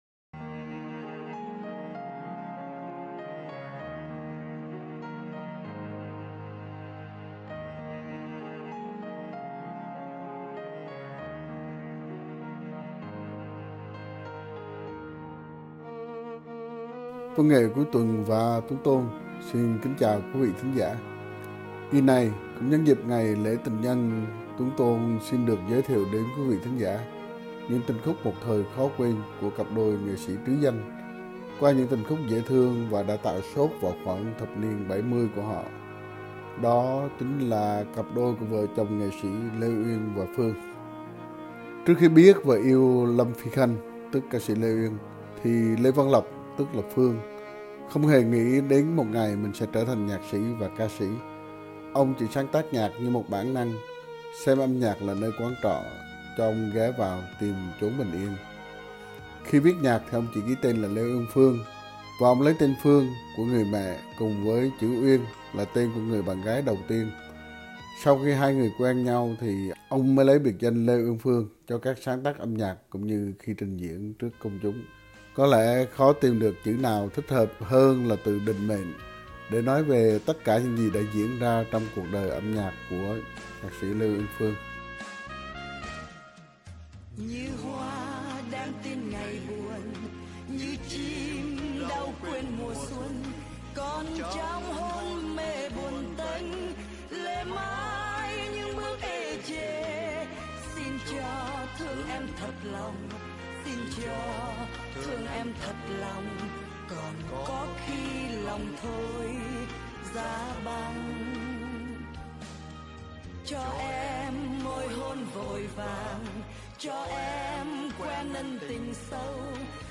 những bản tình ca duyên dáng, bất hủ viết về tình yêu